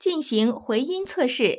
ivr-to_do_a_freeswitch_echo_test.wav